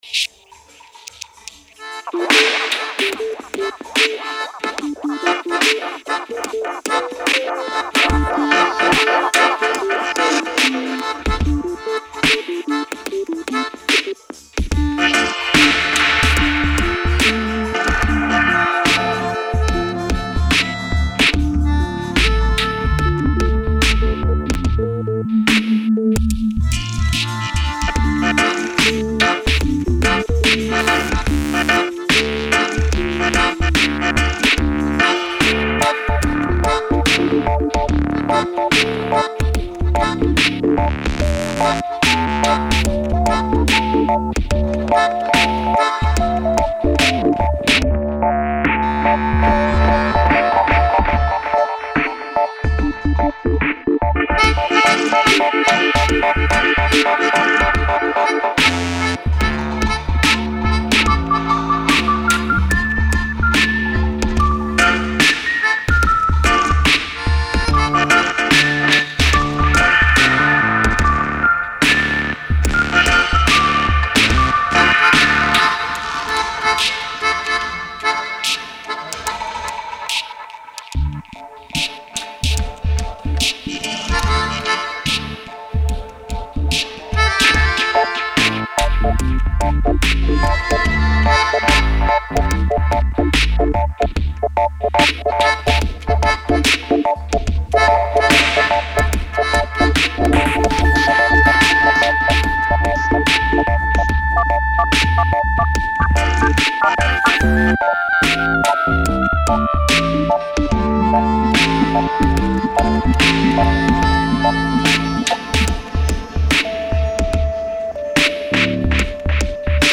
Drums & Space echo :
Organ, BassSynth & weird noises:
Melodica, Dubs & arrangements :